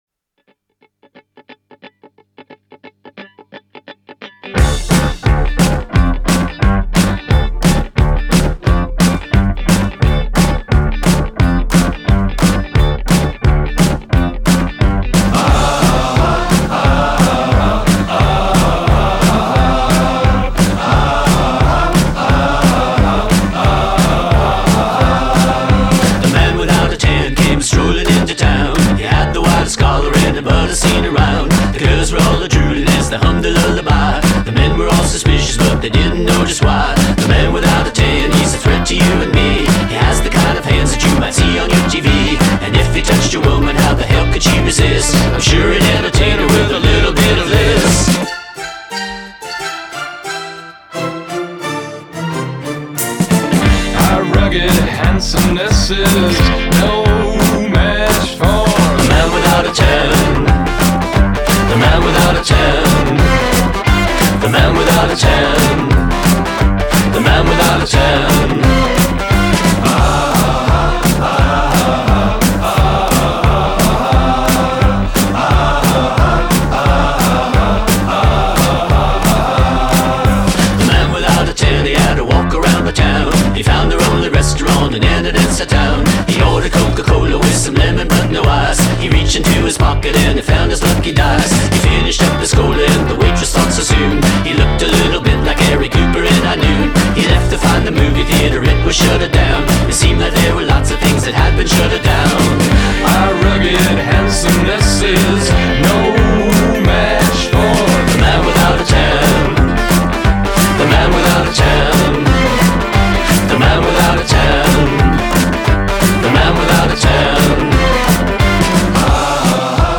Genre: Indie Rock